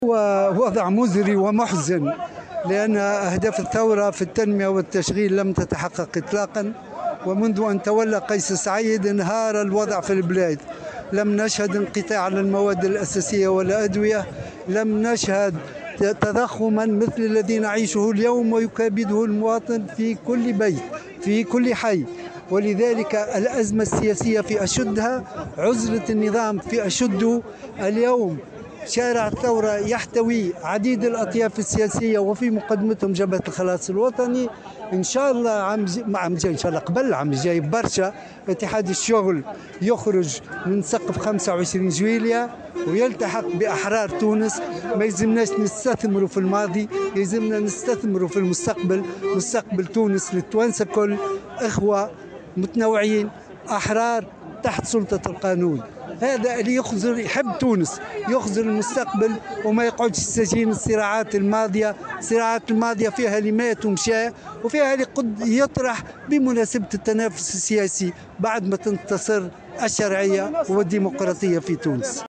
واعتبر الشابي، في تصريح لمراسلة الجوهرة أف أم، خلال مسيرة لجبهة الخلاص انطلقت من ساحة "الباساج"، وصولا إلى شارع الحبيب بورقيبة بالعاصمة، أن عزلة النظام قد بلغت أشدّها، خاصة وأن "شارع الثورة" احتضن اليوم مختلف الأطياف السياسية وفي مقدمتهم جبهة الخلاص.